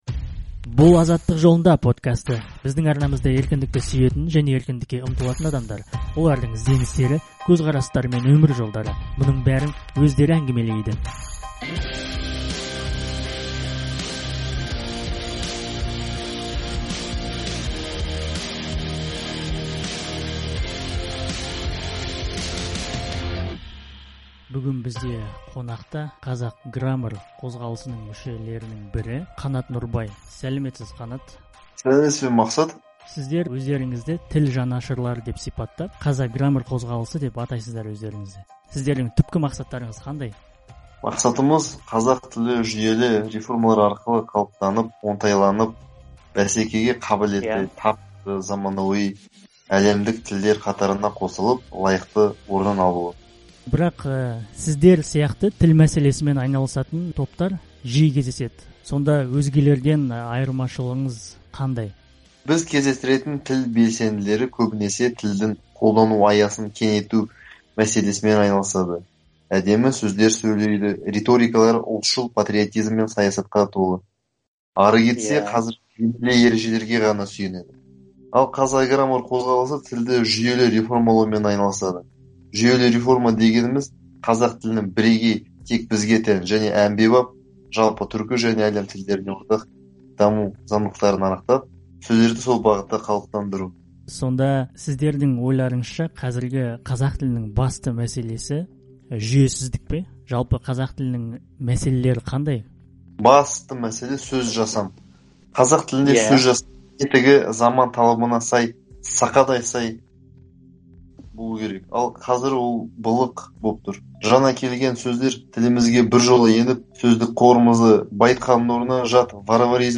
Латынға көшу һәм қазақ тіліндегі көне сөздер. Kazak Grammar өкілімен сұхбат